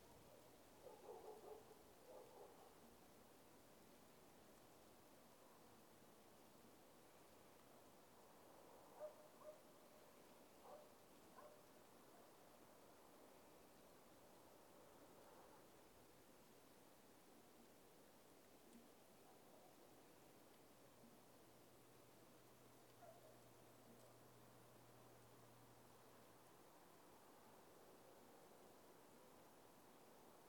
silence-in-the-forest
Category: Sound FX   Right: Personal